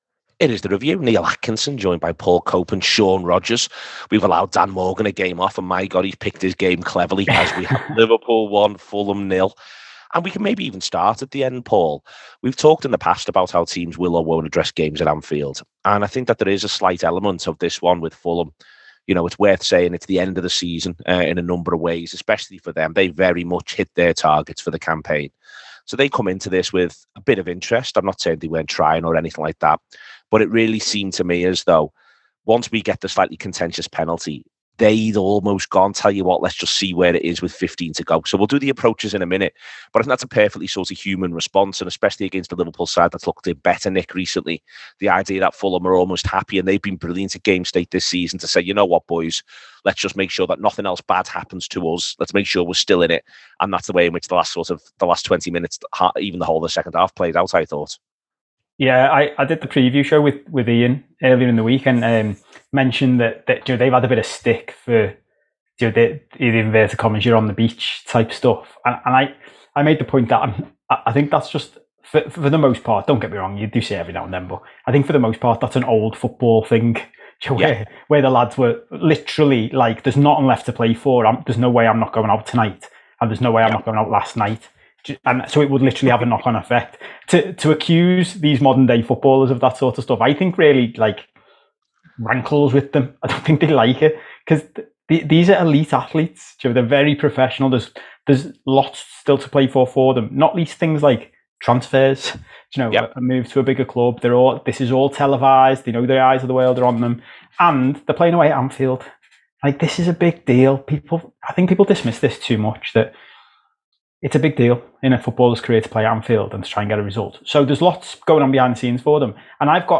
Below is a clip from the show – subscribe for more review chat around Liverpool 1 Fulham 0…